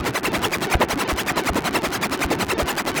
Index of /musicradar/rhythmic-inspiration-samples/80bpm
RI_ArpegiFex_80-02.wav